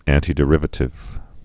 (ăntē-dĭ-rĭvə-tĭv, ăntī-)